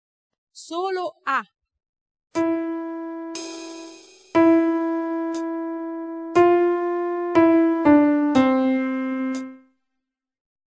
Tutti i nostri dettati, fino alle modulazioni sono stati registrati sopra un ritmo di metronomo che pulsa nelle unità di tempo per le misure semplici e nelle suddivisioni per le misure composte.
Oltre a questo,  per dar modo all'allievo di "entrare preciso" con i movimenti della mano nell'andamento del dettato, è stato inserito  lo slancio, trattasi di un colpo di piatto della batteria che si trova esattamente un battito prima della frazione da scrivere mentre due battiti prima si ascolta la prima nota del dettato (vedi la figura sotto).